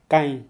This is a tenative list of words elicited in Shua.
Some recordings are suboptimal and there are errors needing to be corrected. Tone marks are approximate, and the levels indicated here are from highest to lowest s,h,m,l,x. Nasalization is marked with N at the end of the syllable; root-medially, /b/ is pronounced as [β].